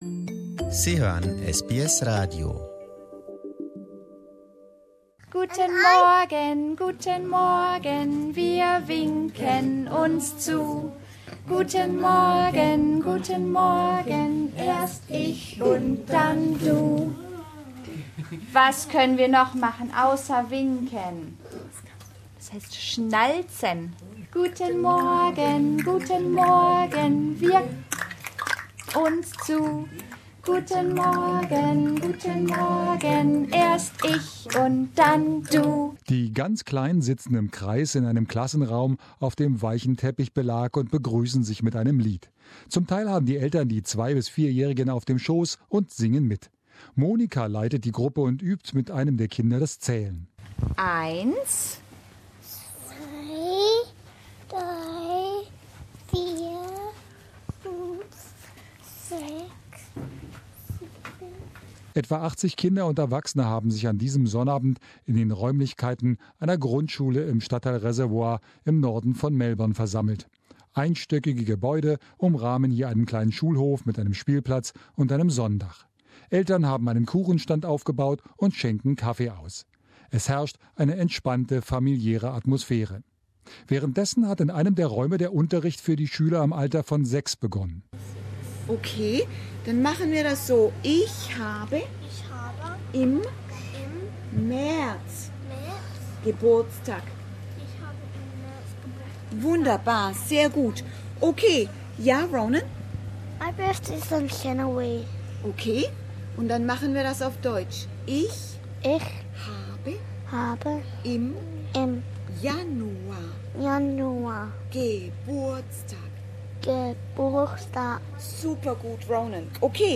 Letzten Samstag war Tag der offenen Tür.